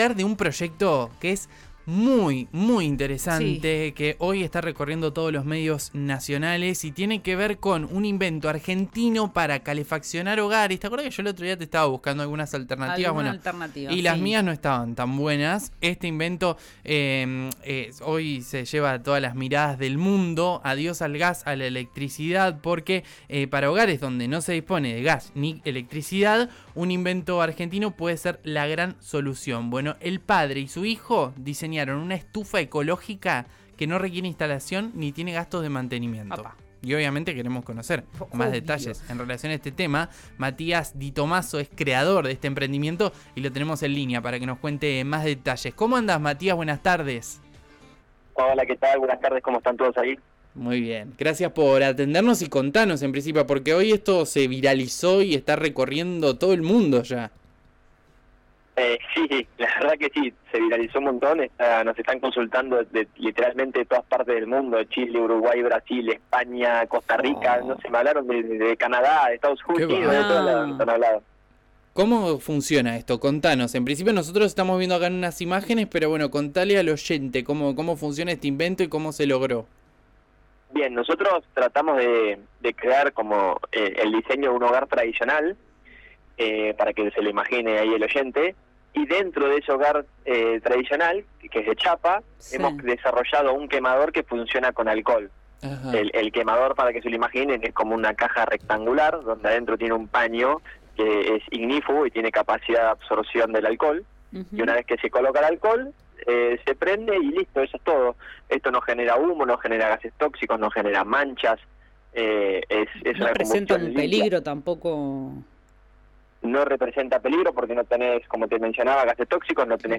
fue compartido en el programa El diario del mediodía de RÍO NEGRO RADIO, donde explicó cómo esta propuesta sustentable fue ganando terreno tanto en zonas rurales como en barrios cerrados.